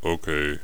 khanat-sounds-sources/sound_library/voices/voice_acknowledgement/ack5.wav at f42778c8e2eadc6cdd107af5da90a2cc54fada4c